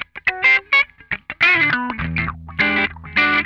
CRUNCHWAH 7.wav